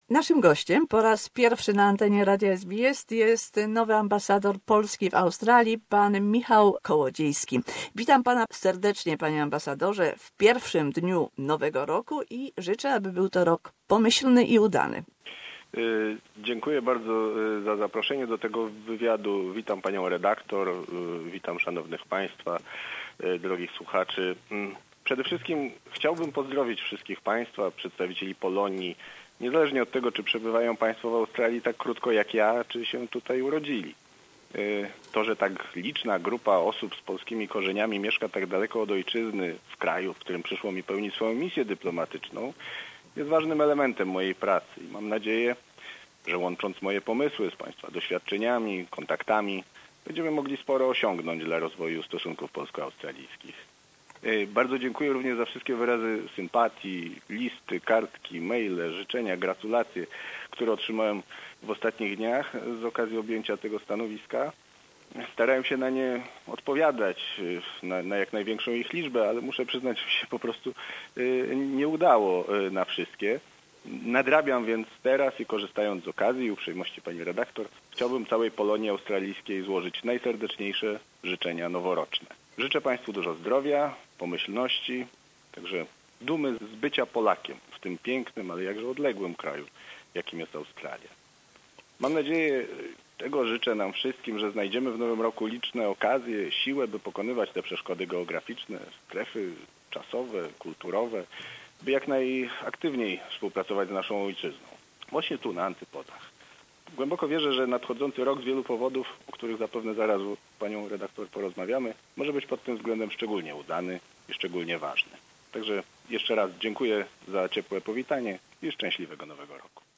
A conversation with ambassador of Poland Michał Kołodziejski who commenced his mission in Australia at the end of November 2017.